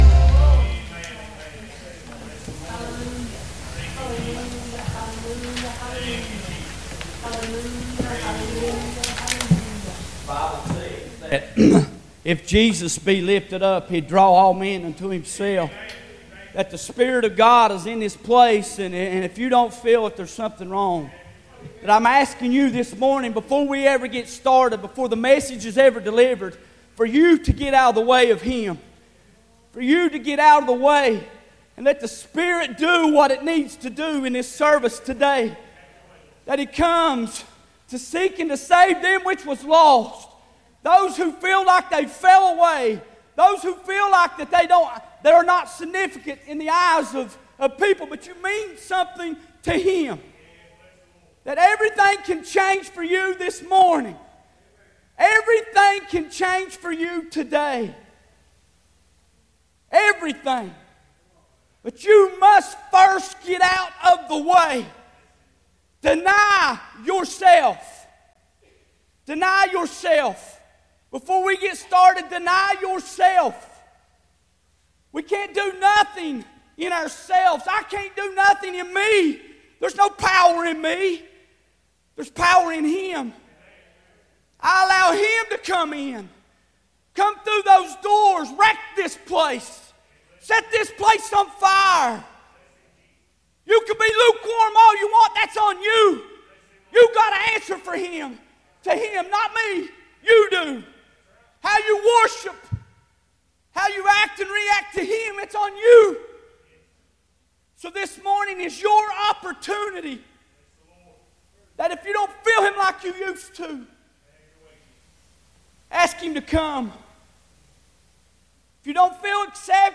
Preaches this Morning in Regards to The Samaritan Woman At the Well in Today's Worship Service.